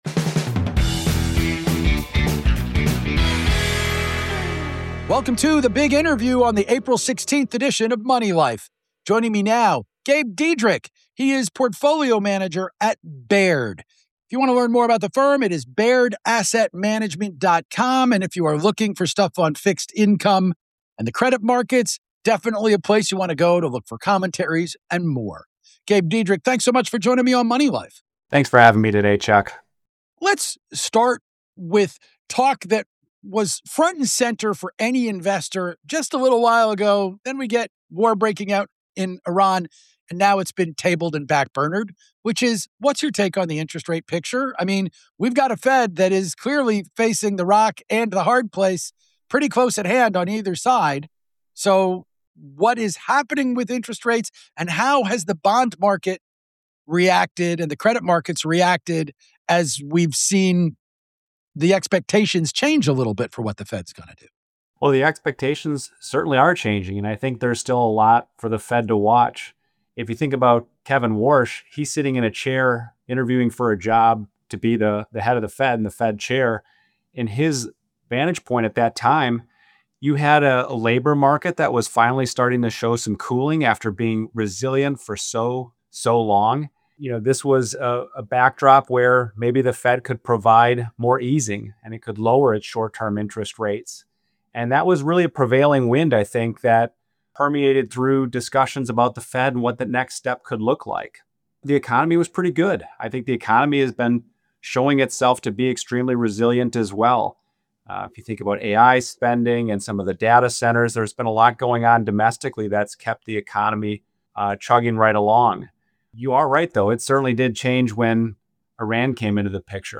Key Takeaways from the interview: